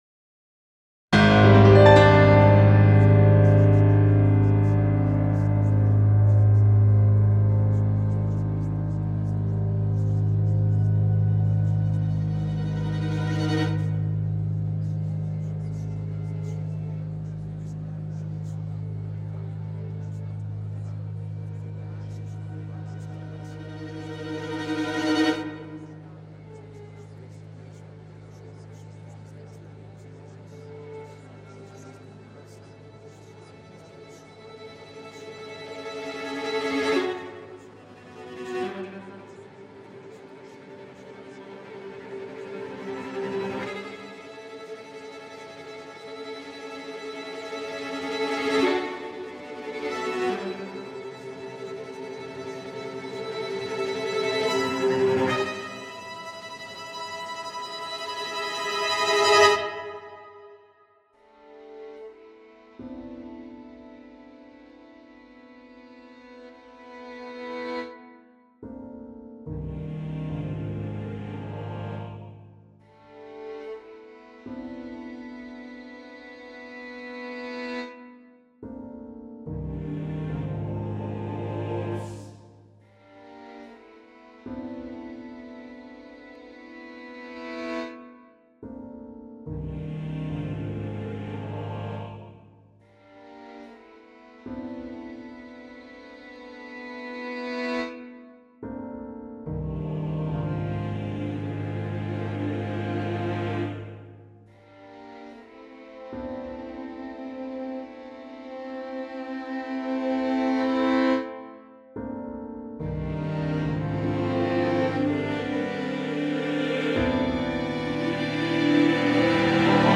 a choral and chamber music composition in nine movements